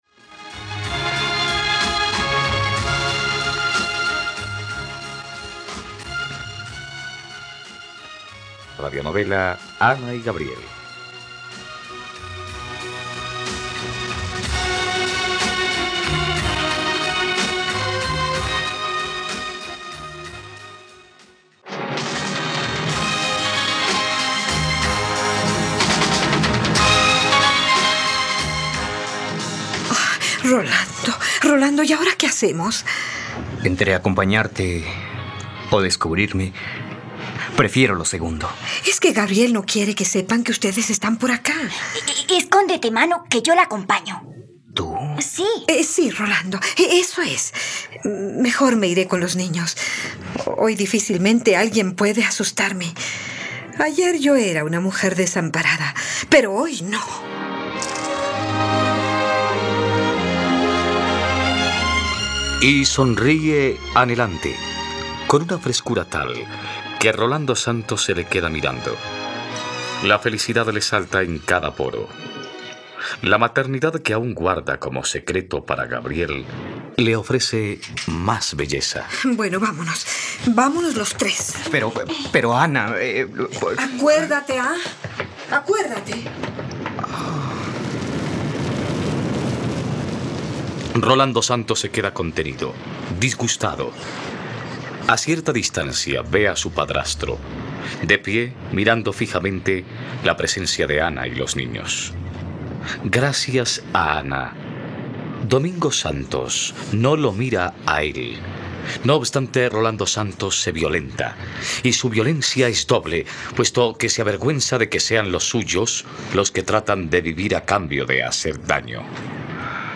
..Radionovela. Escucha ahora el capítulo 115 de la historia de amor de Ana y Gabriel en la plataforma de streaming de los colombianos: RTVCPlay.